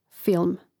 fȉlm film